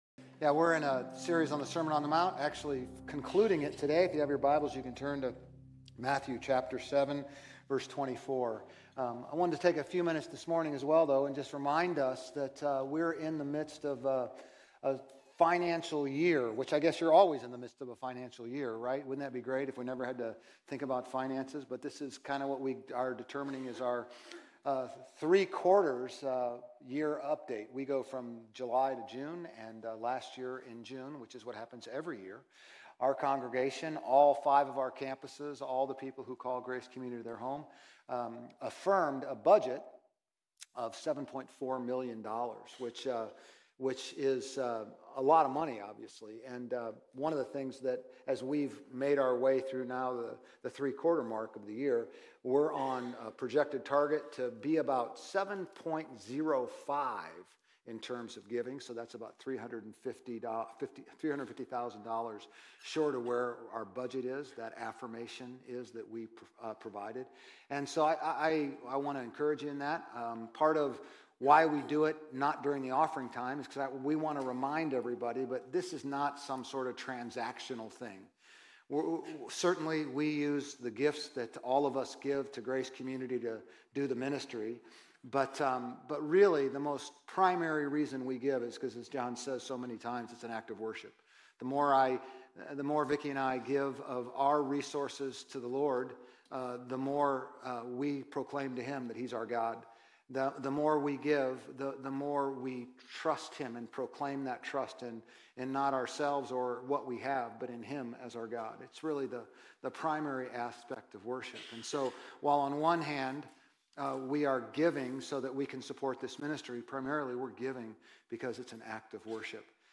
Grace Community Church Old Jacksonville Campus Sermons 4_6 Old Jacksonville Campus Apr 07 2025 | 00:33:59 1x 00:00 / 00:33:59 Subscribe Share RSS Feed Share Link Embed